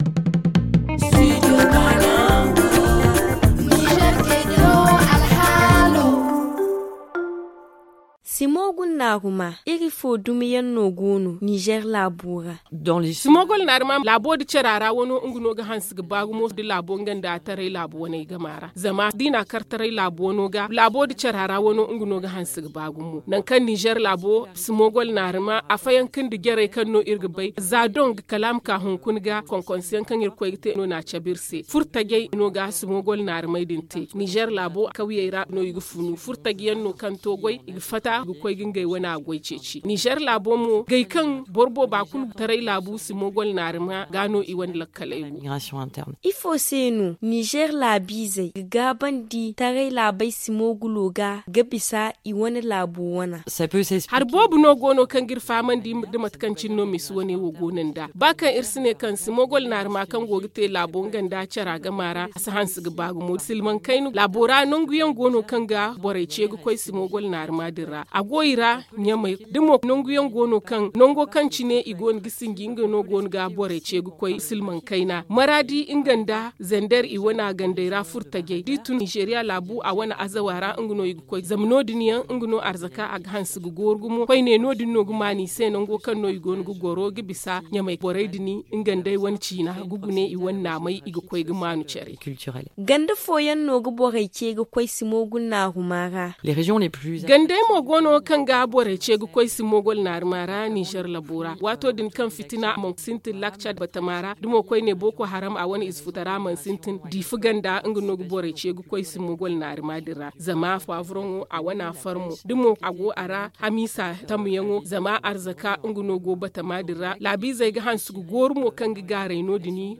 Le magazine en zarma